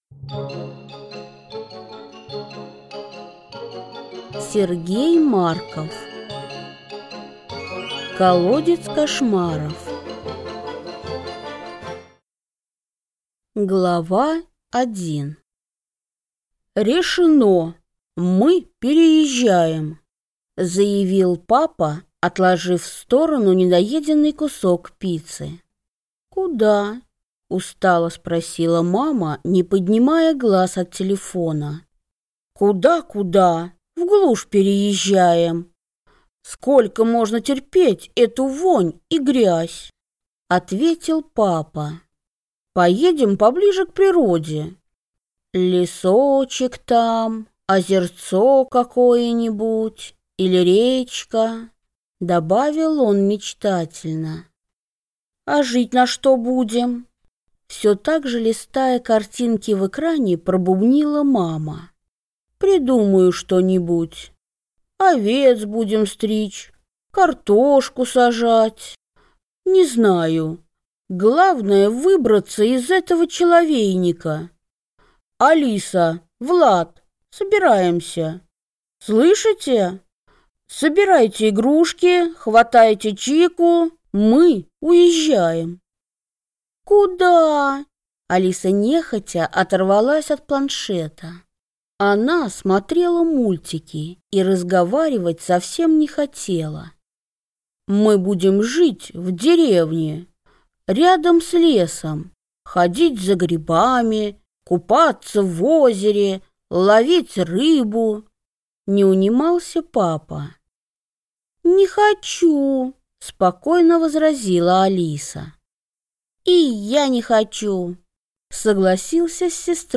Аудиокнига Колодец Кошмаров | Библиотека аудиокниг